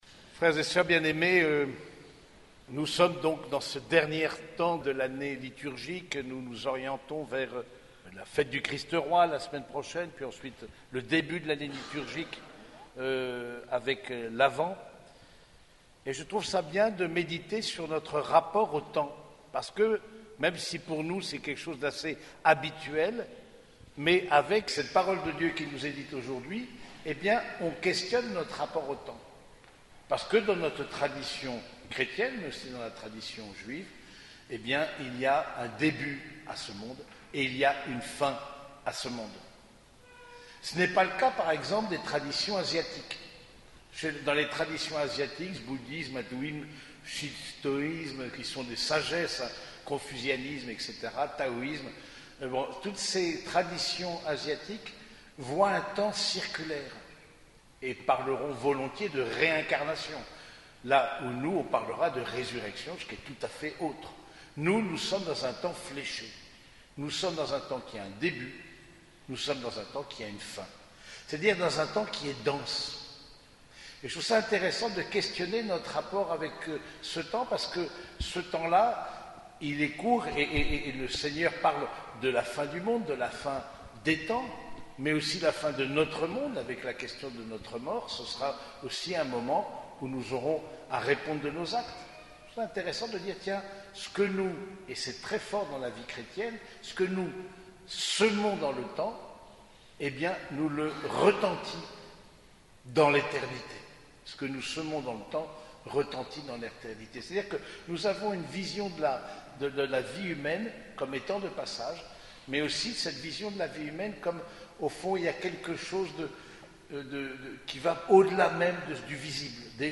Homélie du 33e dimanche du Temps Ordinaire